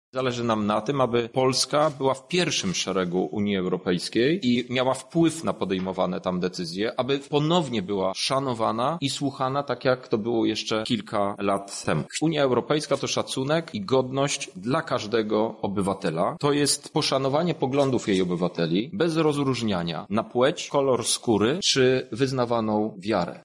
-mówi europoseł Krzysztof Hetman.